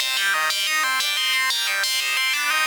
Index of /musicradar/shimmer-and-sparkle-samples/90bpm
SaS_Arp05_90-C.wav